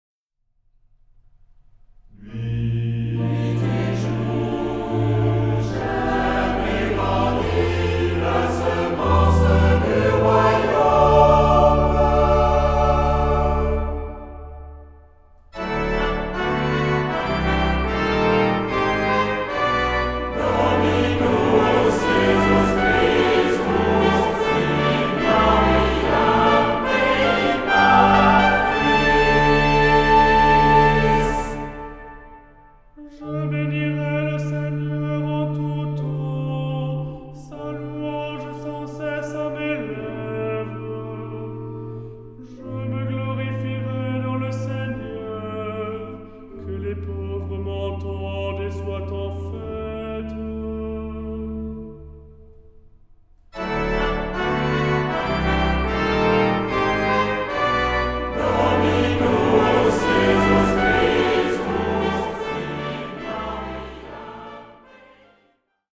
Genre-Stil-Form: Tropar ; Psalmodie
Charakter des Stückes: andächtig
Chorgattung: SATB  (4 gemischter Chor Stimmen )
Instrumente: Orgel (1) ; Melodieinstrument (1)
Tonart(en): dis-moll ; D-Dur